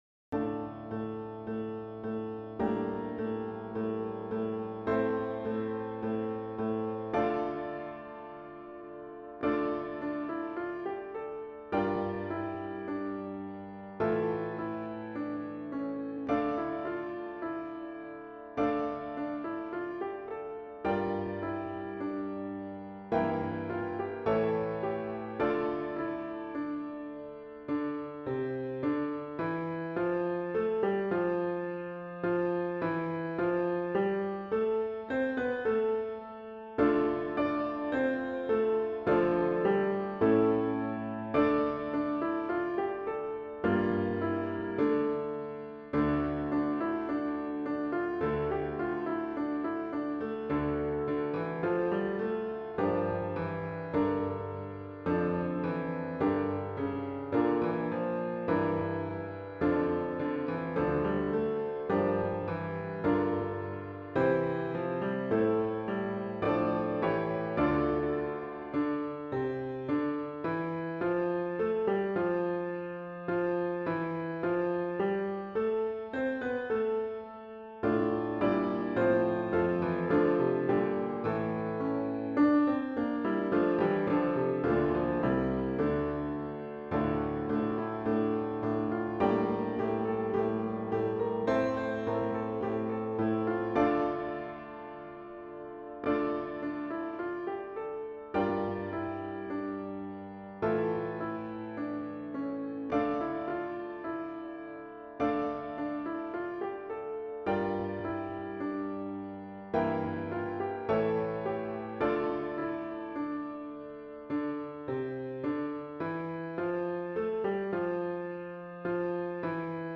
Hand Bells/Hand Chimes